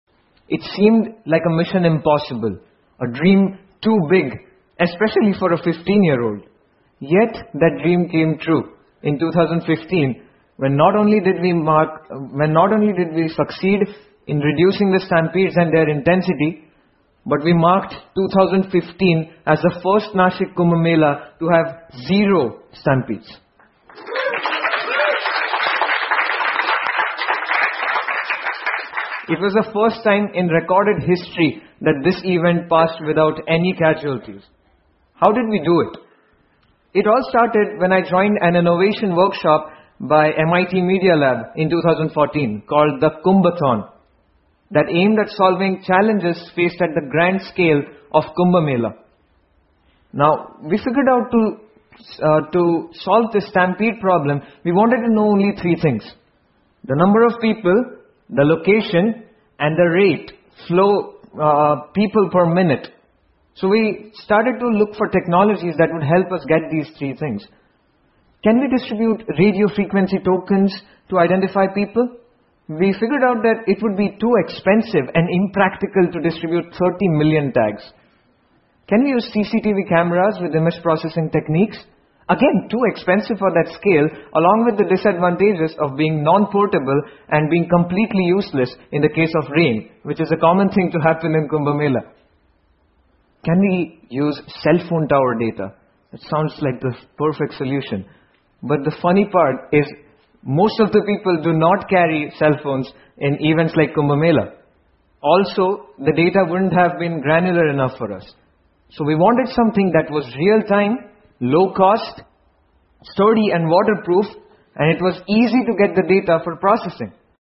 TED演讲:一个能防止人群踩踏事件的救生发明() 听力文件下载—在线英语听力室